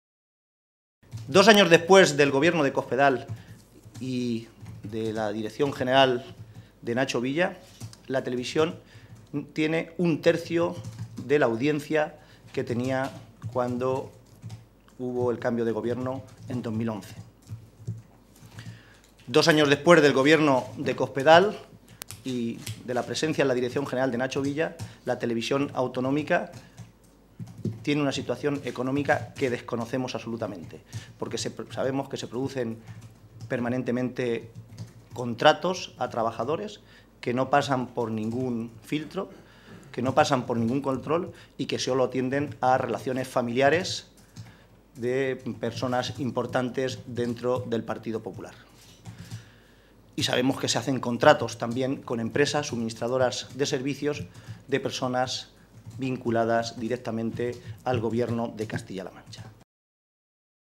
José Manuel Caballero, secretario General del Grupo Parlamentario Socialista
Cortes de audio de la rueda de prensa